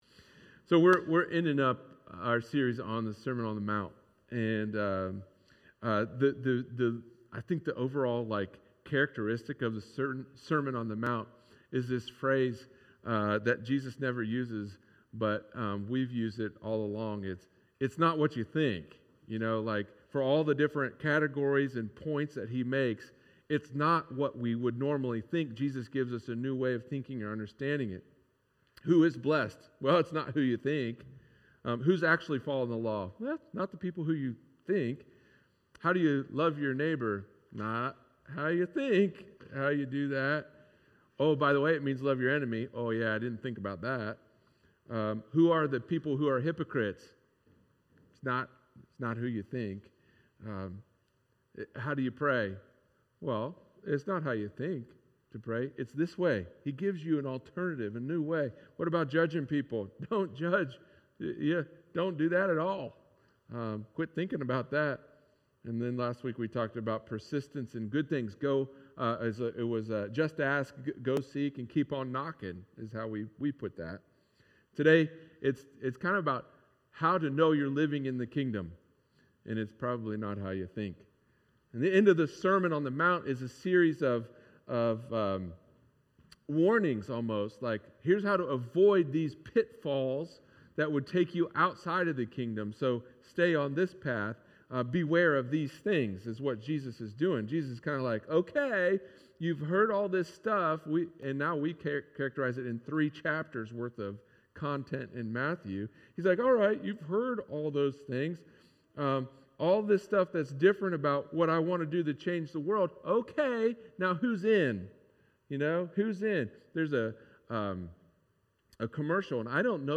sermon-on-the-mount-follow-the-blueprint.mp3